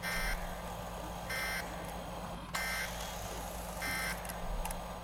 Camera.ogg